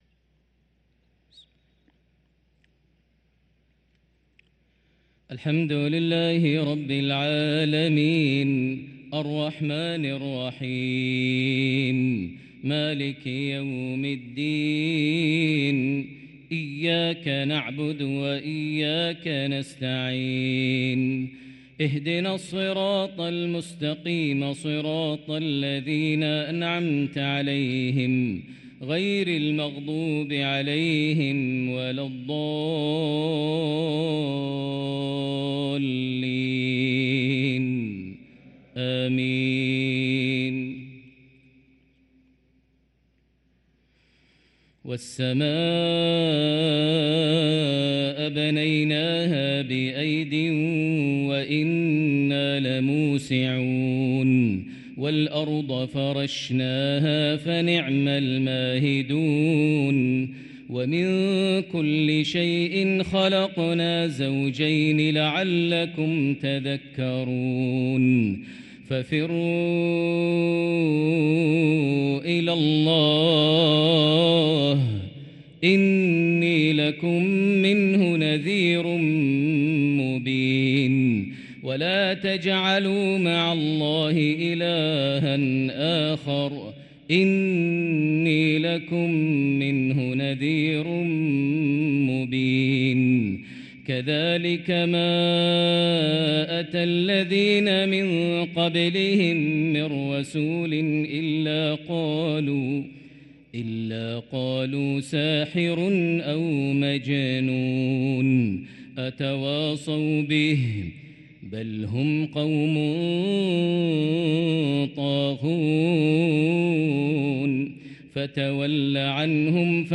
صلاة المغرب للقارئ ماهر المعيقلي 30 ربيع الأول 1445 هـ
تِلَاوَات الْحَرَمَيْن .